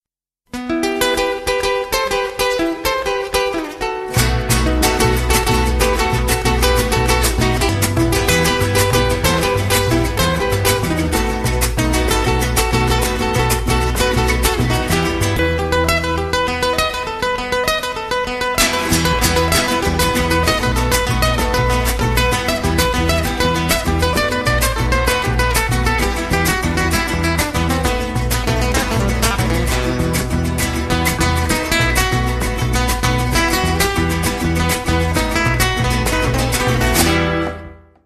Рингтоны » Саундтреки